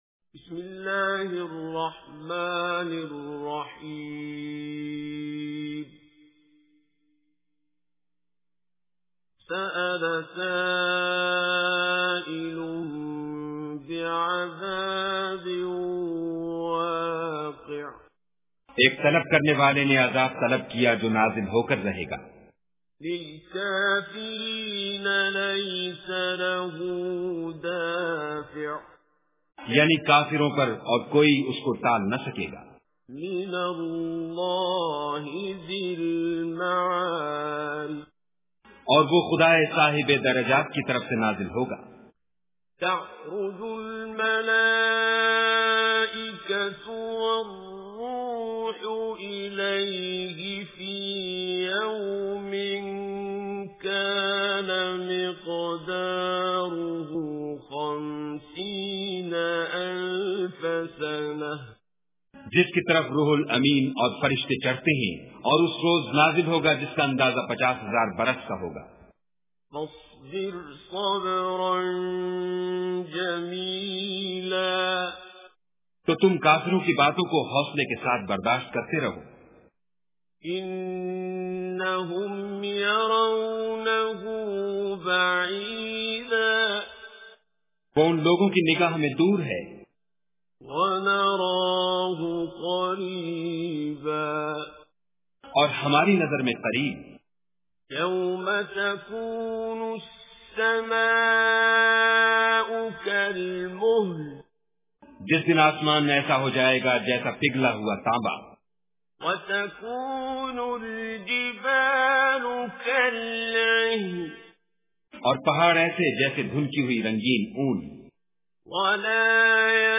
Sura al-Maarij Recitation with Urdu Translation
Surah Al Maarij is 70 Surah of Holy Quran. Listen online and download mp3 tilawat / recitation of Surah Maarij in the voice of Qari abdul Basit As Samad.